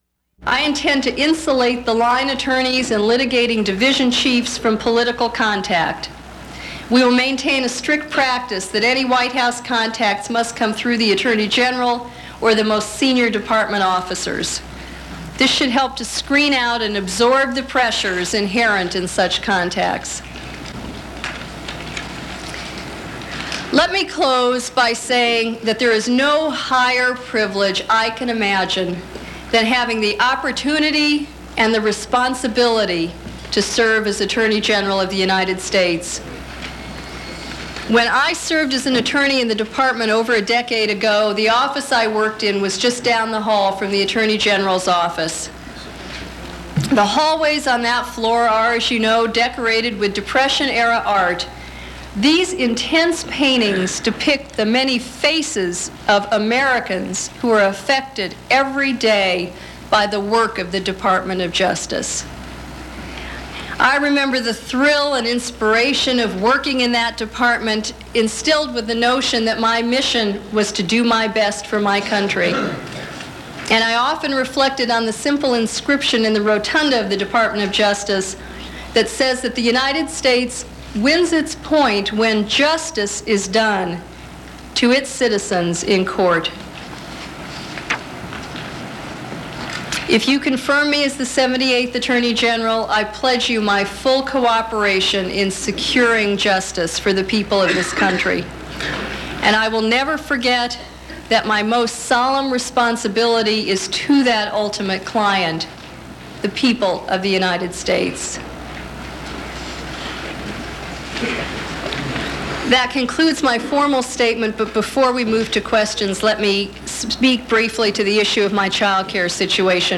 Confirmation hearing for Attorney General-designate Zoe Baird